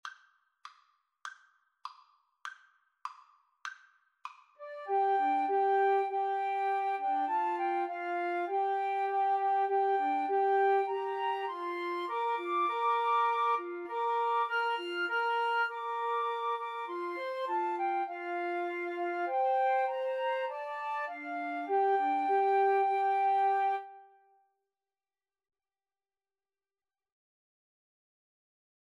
Free Sheet music for Flute Trio
Moderato
Bb major (Sounding Pitch) (View more Bb major Music for Flute Trio )